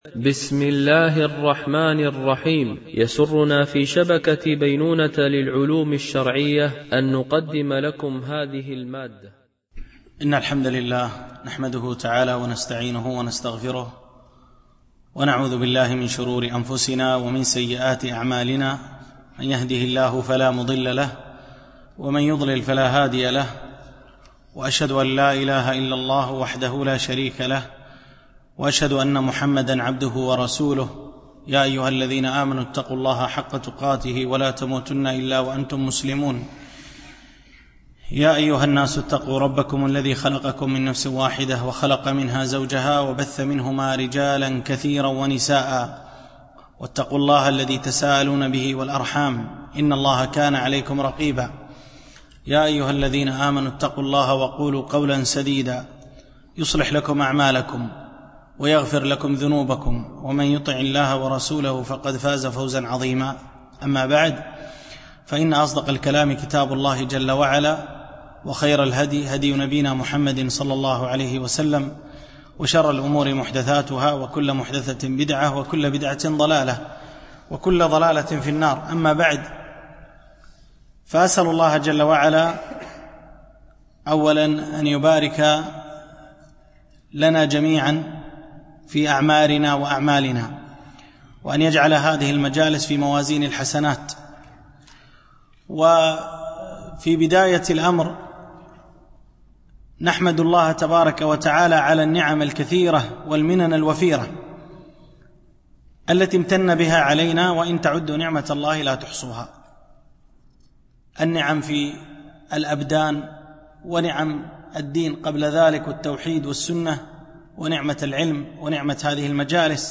شرح قاعدة في الصبر لشيخ الإسلام ابن تيمية ـ الدرس 1